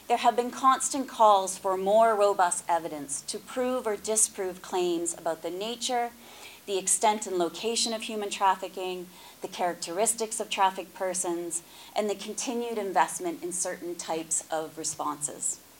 Speaking at the Pacific Regional Forum on Trafficking in Persons & Smuggling of Migrants in Suva today